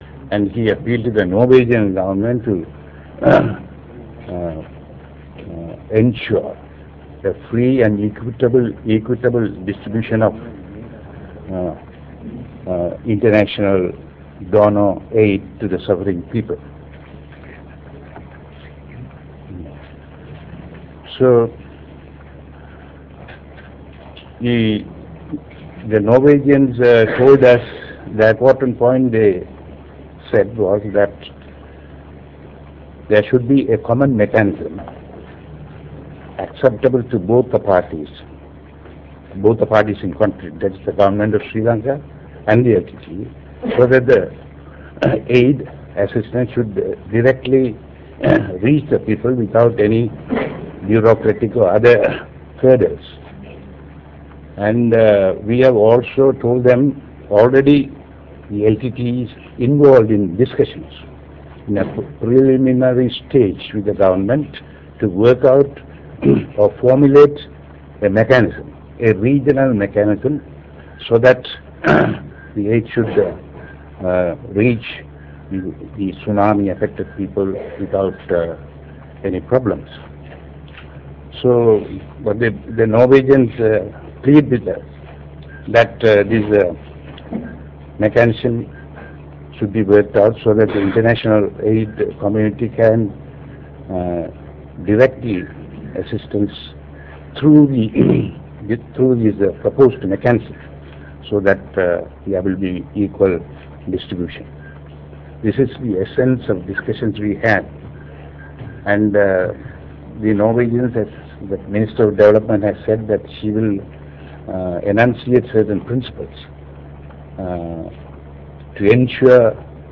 Real Audio Icon Press briefing
Later Mr.Anton Balasingham and Mr.Thamilchelvan held a press briefing at the headquarters of the LTTE Planning Secretariat.